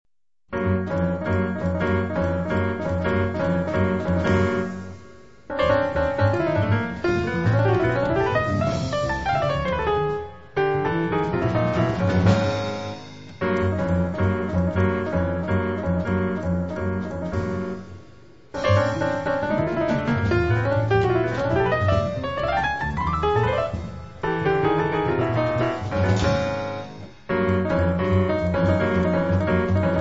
• Jazz